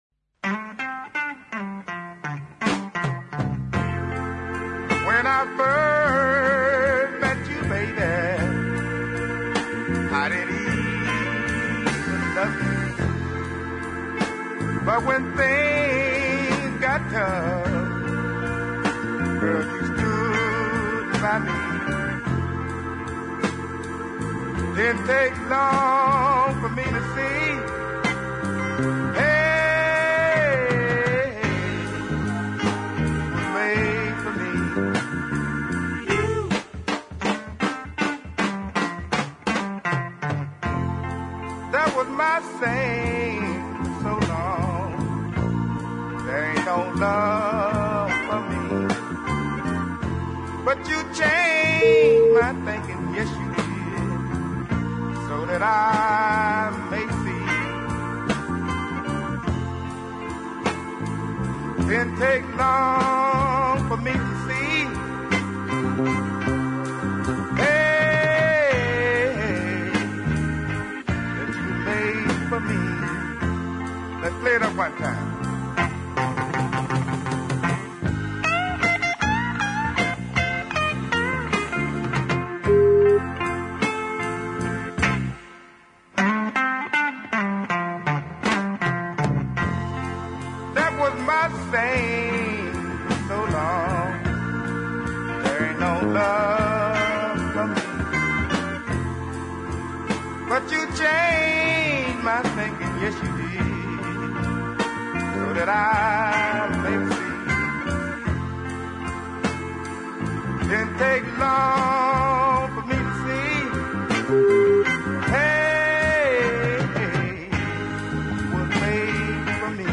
tuneful high baritone vocal
The sensitivity of the small band accompaniment
It’s another ballad winner for me.